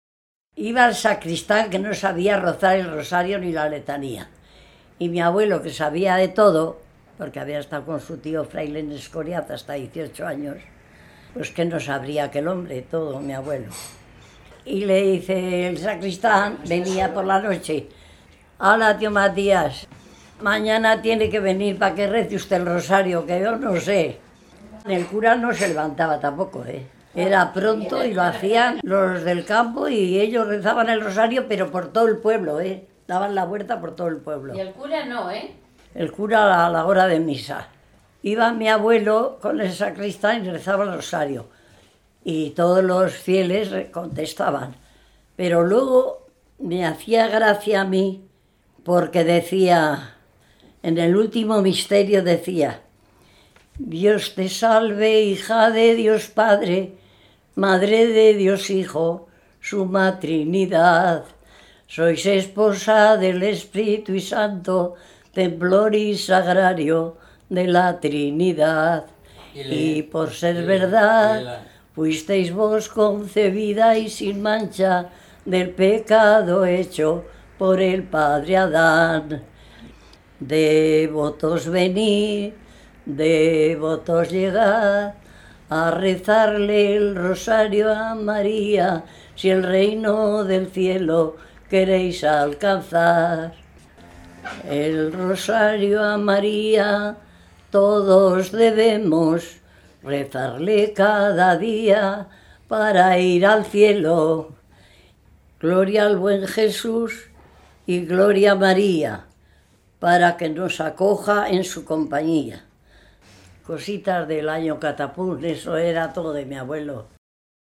Clasificación: Cancionero religioso
Localidad: El Redal
Lugar y fecha de recogida: Logroño, 14 de octubre de 2003